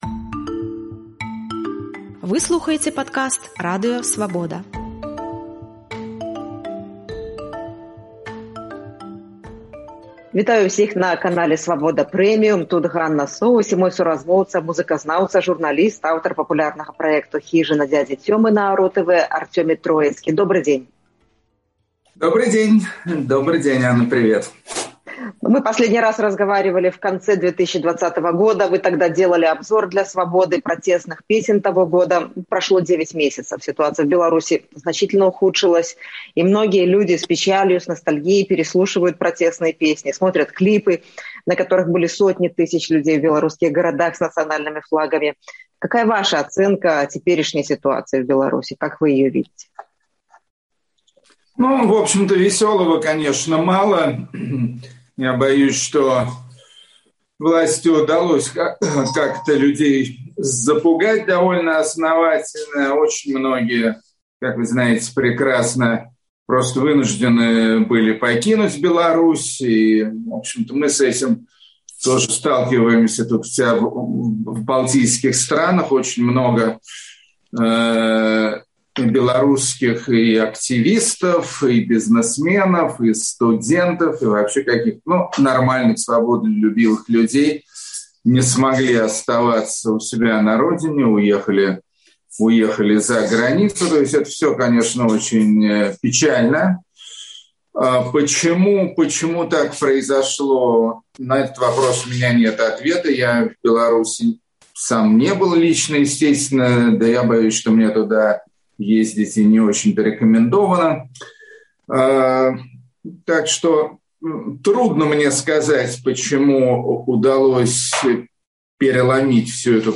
Журналіст, блогер, музычны крытык Арцемій Троіцкі ў эфіры Свабоды Premium разважае, чаму ў беларусаў не атрымалася перамагчы ў жніўні-верасьні 2020-га, якія формы культурнага супраціву могуць быць эфэктыўнымі, хто найбольш пацярпіць ад мігранцкага крызісу і ў які Менск ён хоча прыехаць у агляднай будучыні.